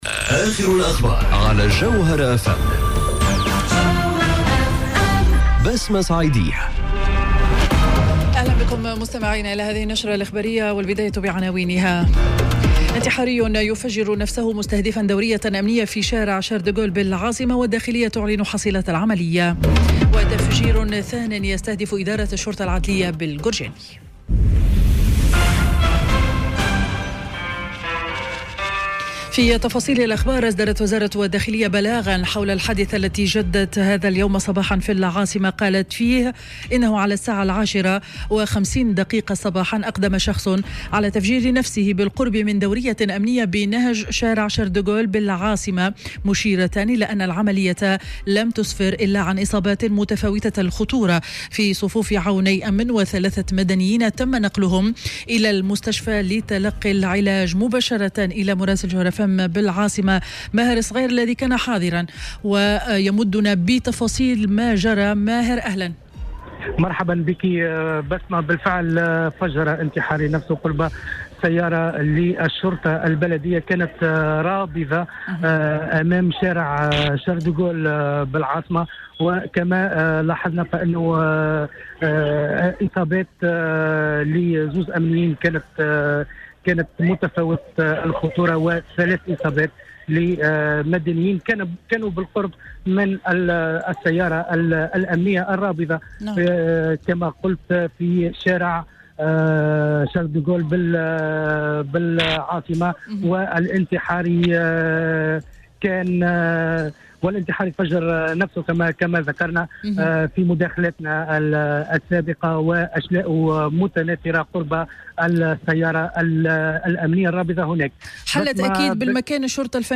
نشرة أخبار منتصف النهار ليوم الخميس 27 جوان 2019